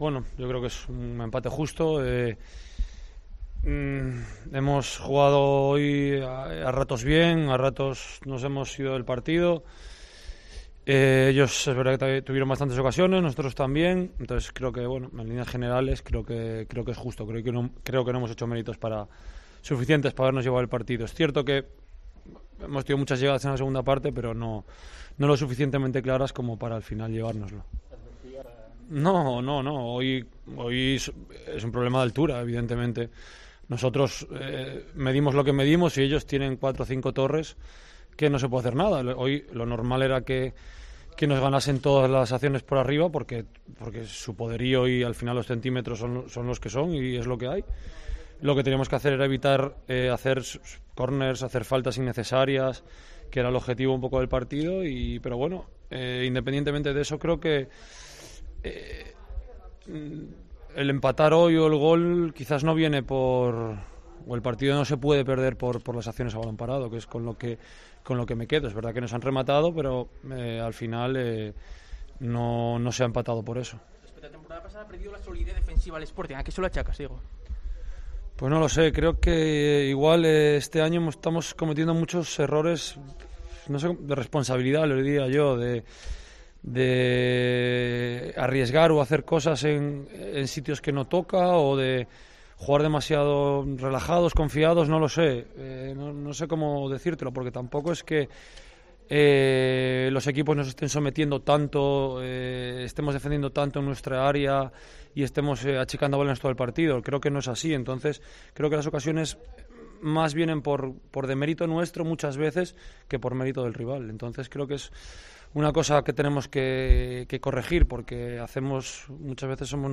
Diego Mariño en zona mixta tras el empate ante el Lugo